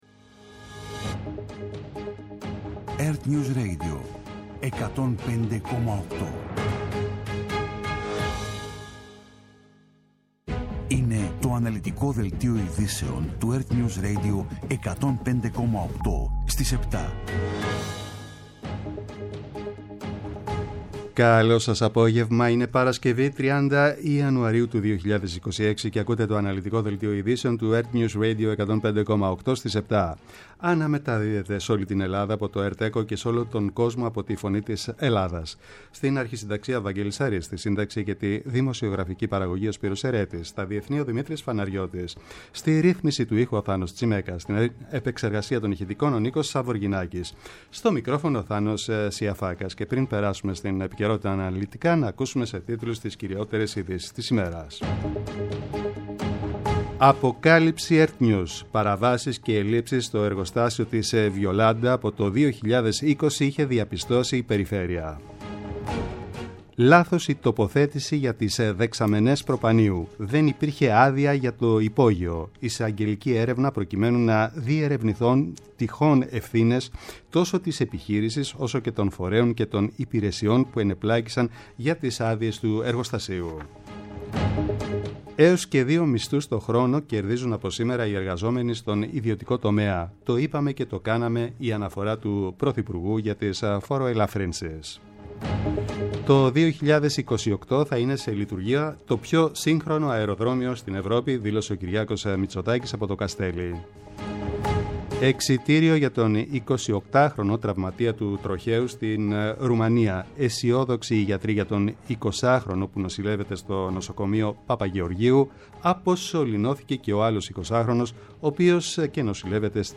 Το αναλυτικό ενημερωτικό μαγκαζίνο στις 19:00. Με το μεγαλύτερο δίκτυο ανταποκριτών σε όλη τη χώρα, αναλυτικά ρεπορτάζ και συνεντεύξεις επικαιρότητας.